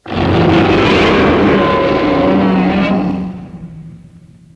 godzilla_spawn_91_01.ogg